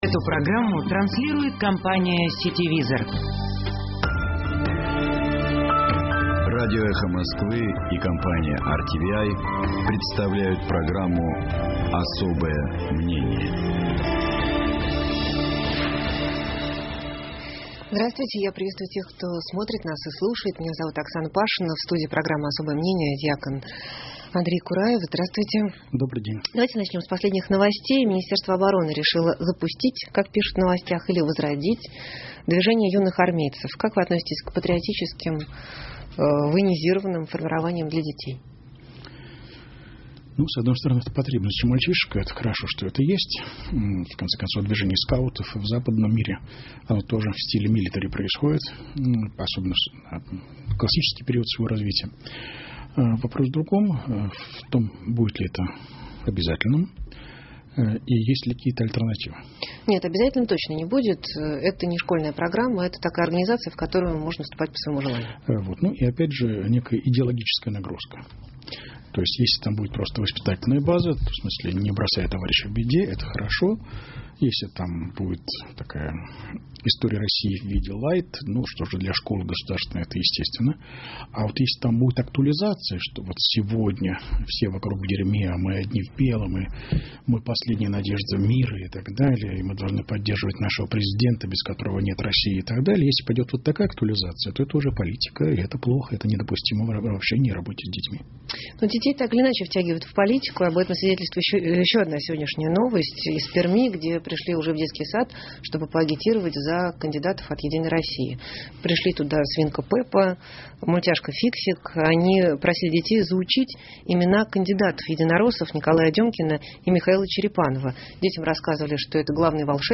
В студии программы «Особое мнение» дьякон Андрей Кураев.